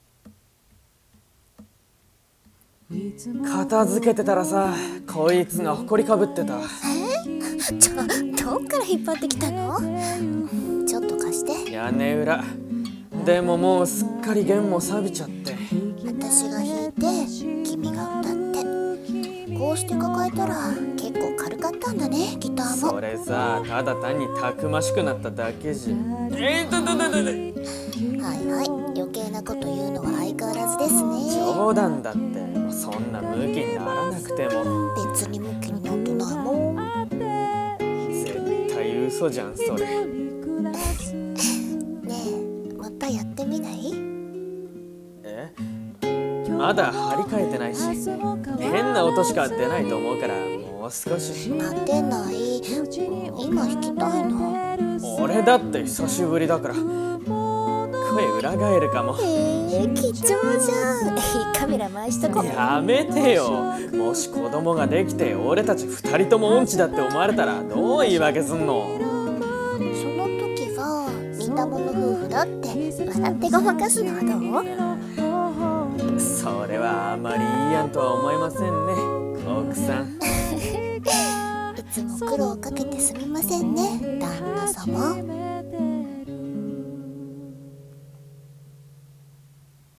二人声劇【ギターと似た者夫婦】